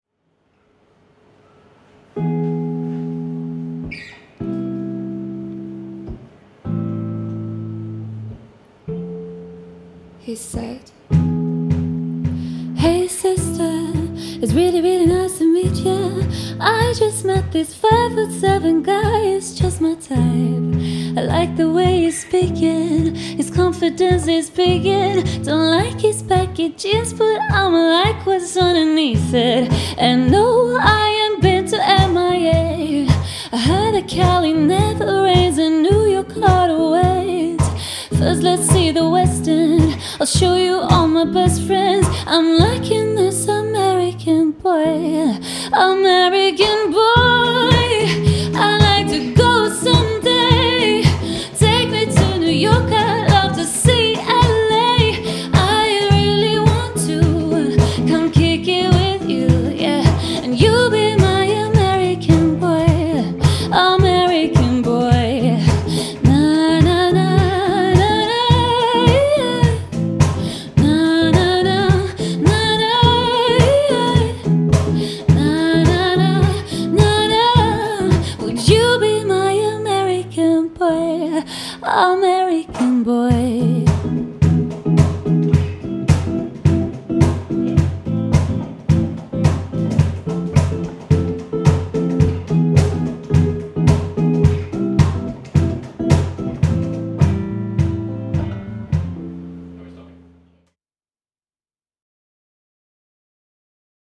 Yng Adult (18-29)
Playful, positive, young adult.
Singing
Pop